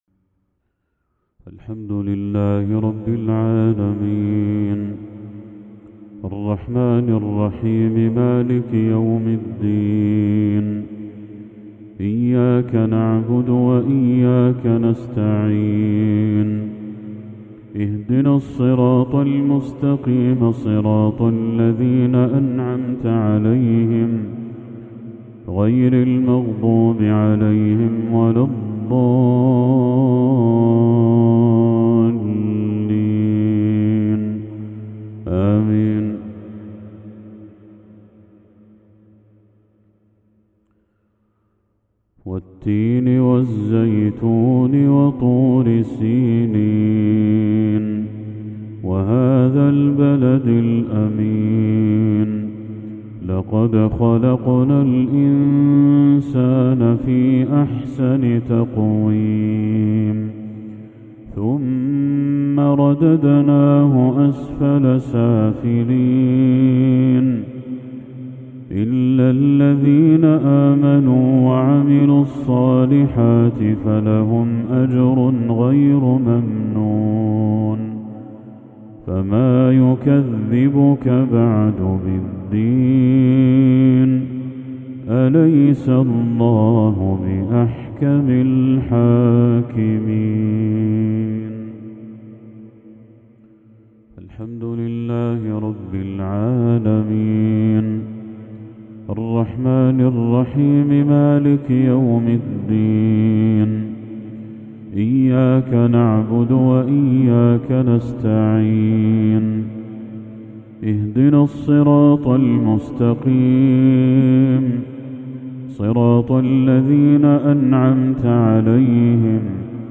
تلاوة لسورتي التين و الهمزة للشيخ بدر التركي | مغرب 3 ذو الحجة 1445هـ > 1445هـ > تلاوات الشيخ بدر التركي > المزيد - تلاوات الحرمين